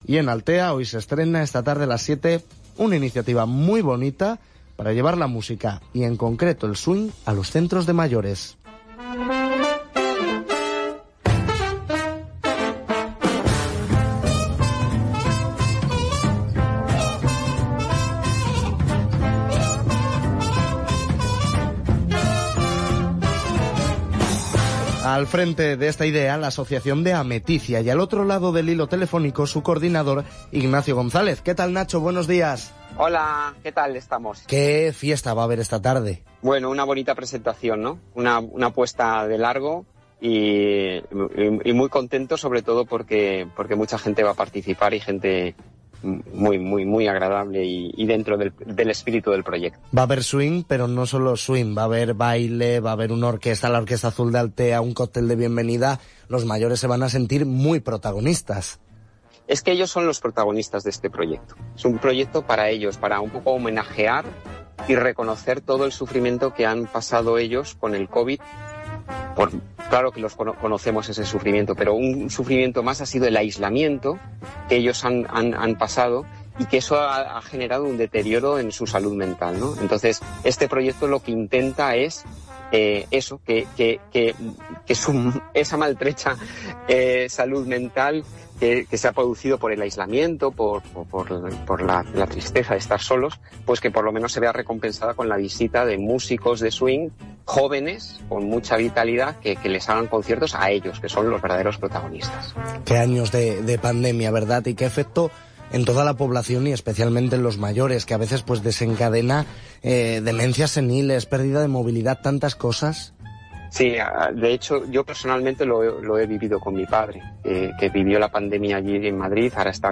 A conversation with Cadena SER about the project and its impact.
entrevista.mp3